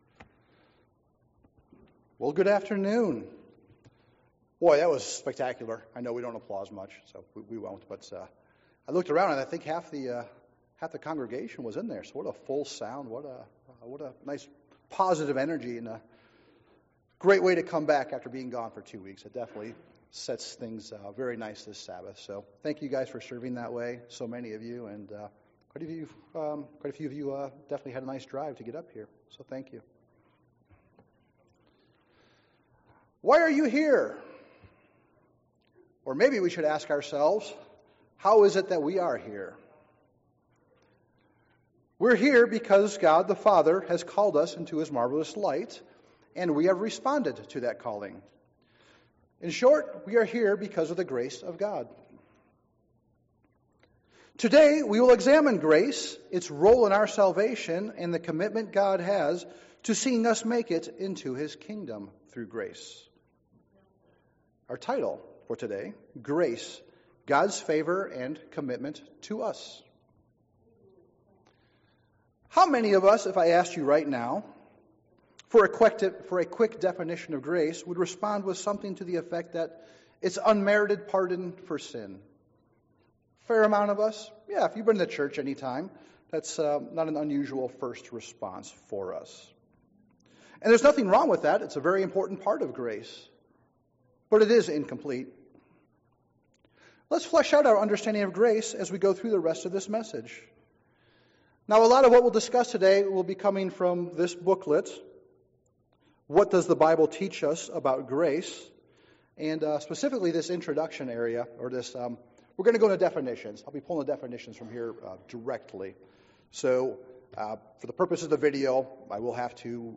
This sermon is a non-exhaustive exposition of grace and how it relates to our salvation. Namely, there is nothing any person can do, without God's grace, that will earn eternal life.